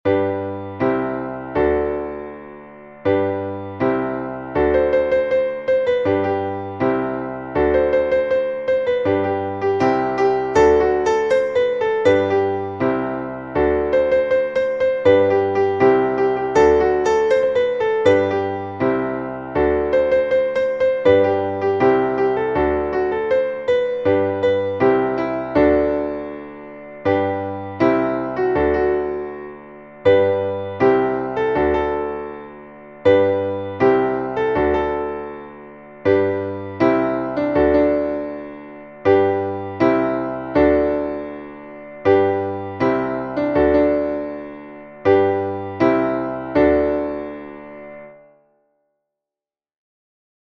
Traditional Folksong from Mexico